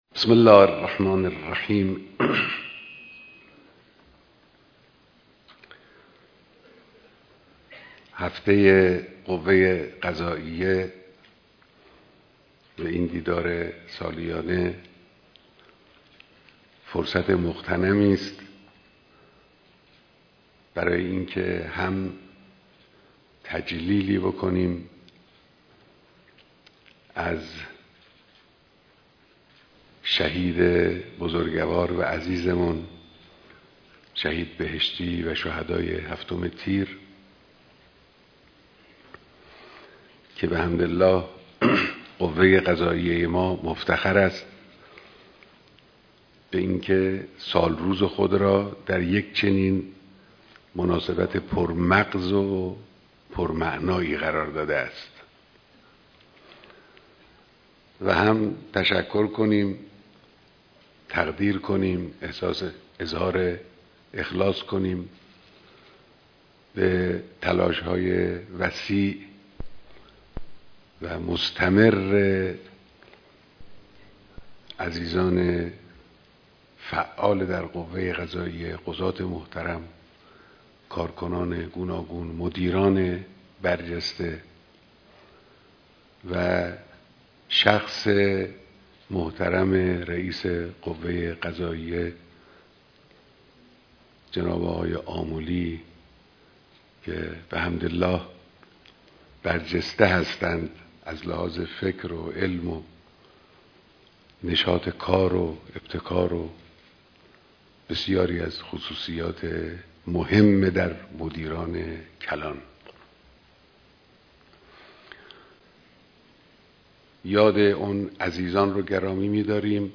بیانات در دیدار مسوولان عالی قضایی